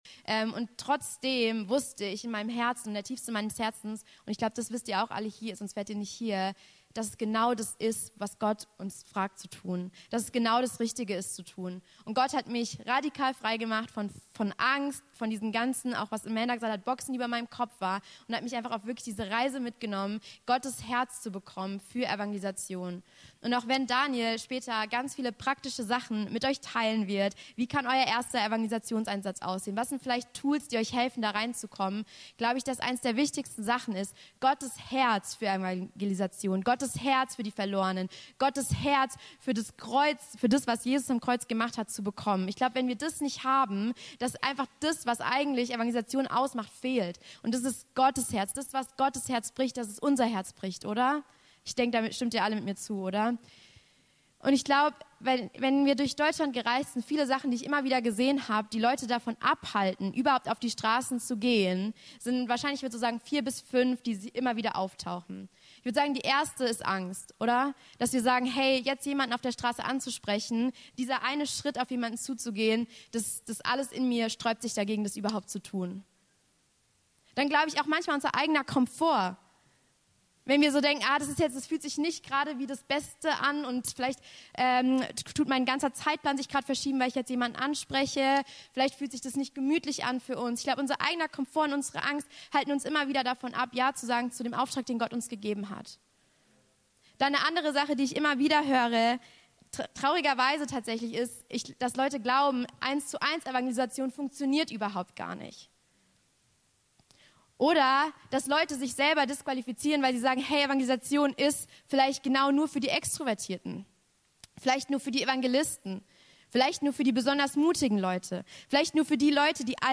Evangelisations-Teaching von Awakening Europe ~ Anskar-Kirche Hamburg- Predigten Podcast